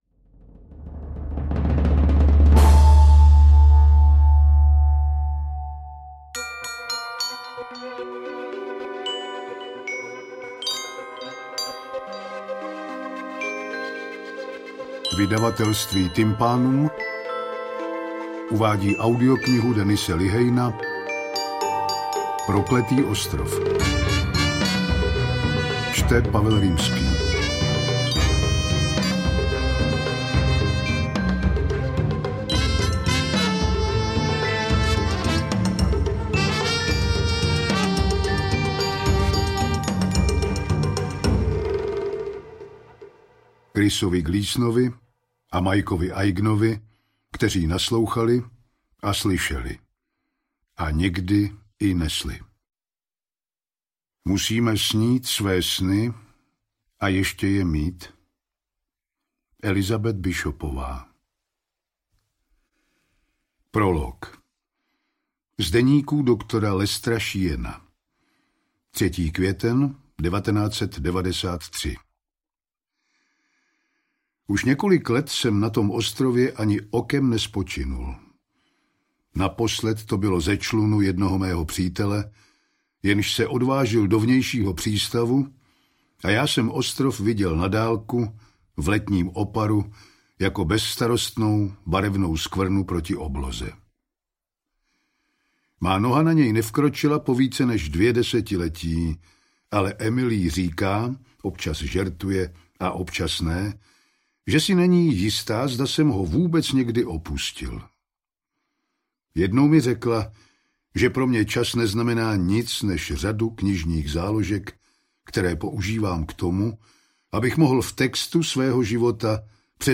Interpret:  Pavel Rímský
AudioKniha ke stažení, 73 x mp3, délka 15 hod. 4 min., velikost 816,3 MB, česky